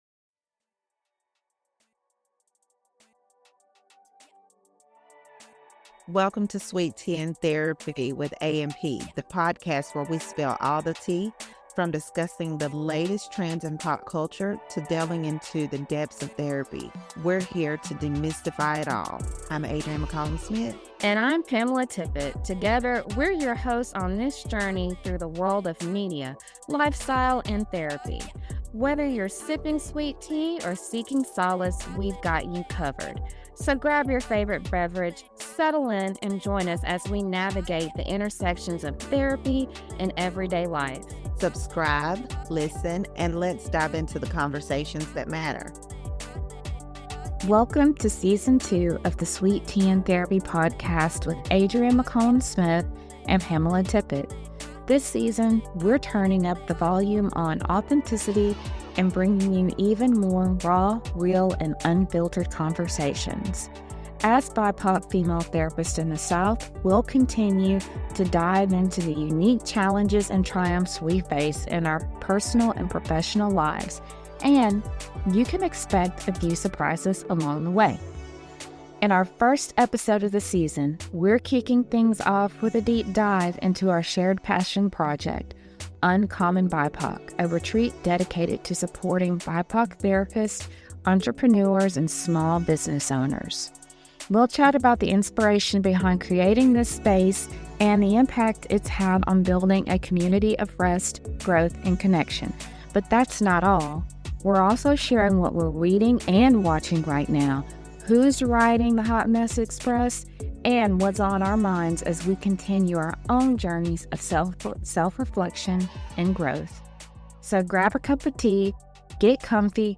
We’re thrilled to be back, bringing you more candid conversations and authentic reflections as BIPOC female therapists navigating life and practice in the South.